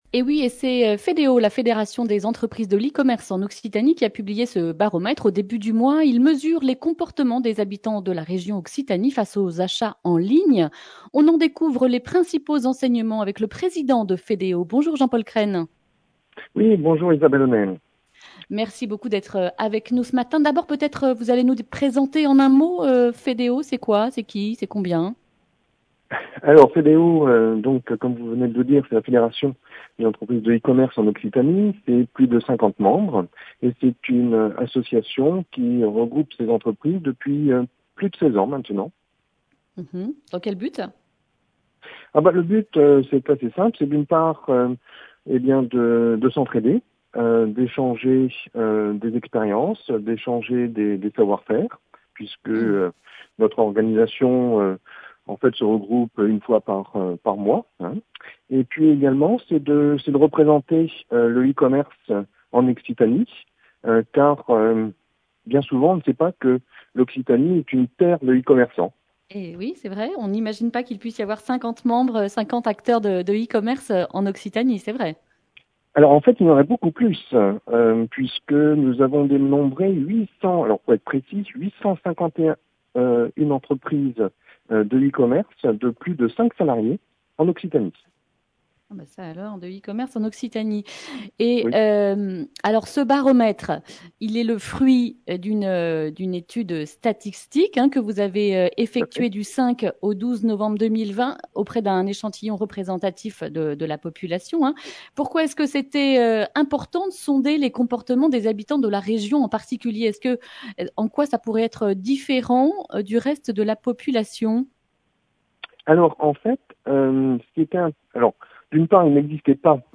mercredi 16 décembre 2020 Le grand entretien Durée 11 min